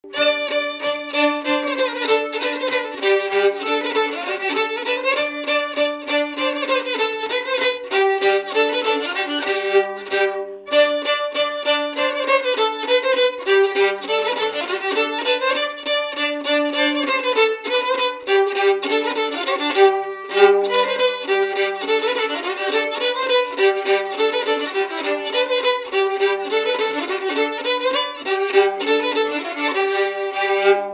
Mémoires et Patrimoines vivants - RaddO est une base de données d'archives iconographiques et sonores.
Résumé instrumental
danse : branle : avant-deux
Pièce musicale inédite